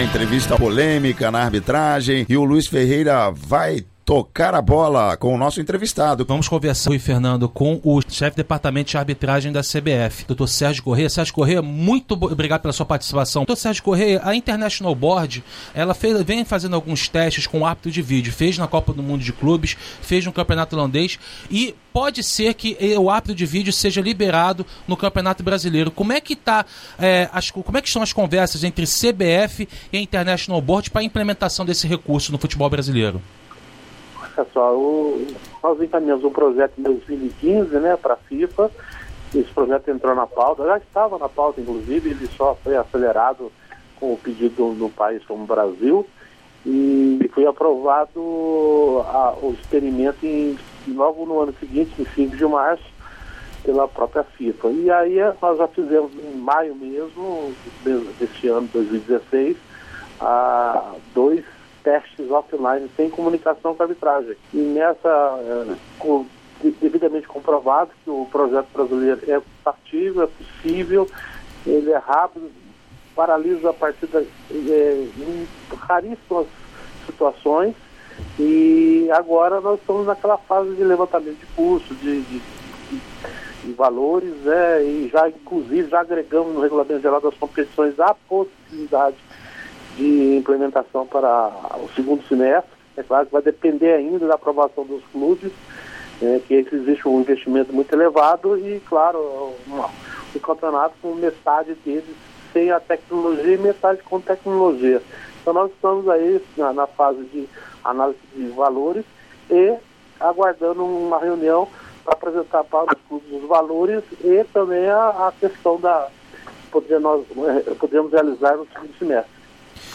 Entrevista: CBF estuda possibilidade de adotar o árbitro de vídeo no Campeonato Brasileiro